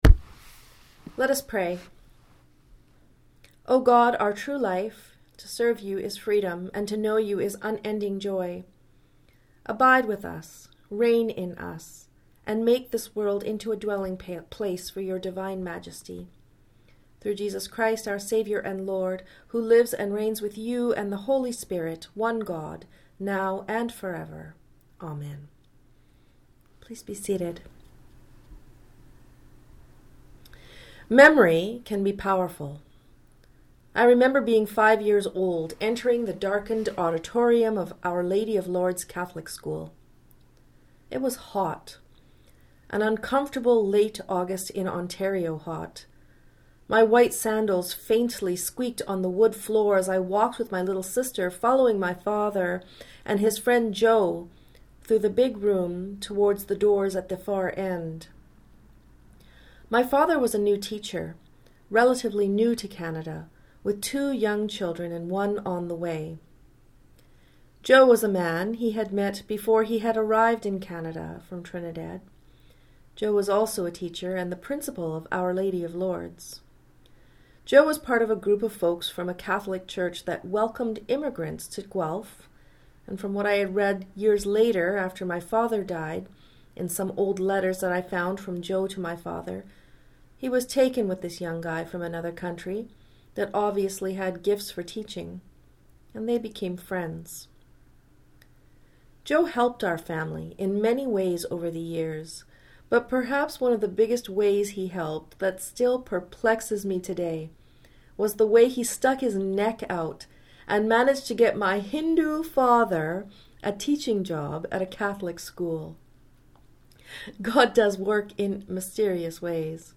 God Beside Us - A Sermon for Christ the King Sunday